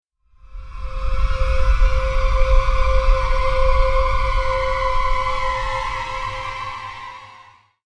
ストリングスクラスター短い.mp3